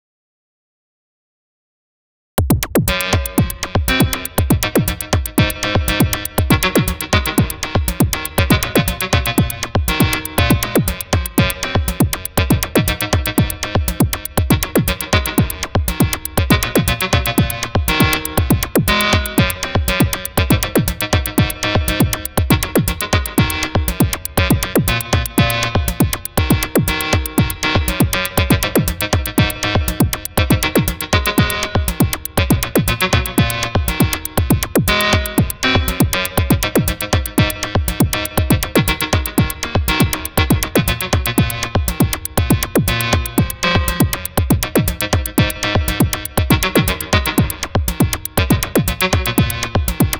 While not perfect, I threw together a quick demo using the motion sequencer built into Arpeggiator. Synth is KASPAR and drums are BlipBox. The synth notes are 100% sequenced by BLEASS Arpeggiator. This demo has 0 mixing/mastering applied, it’s all I could do before work, so please do not judge on sound quality.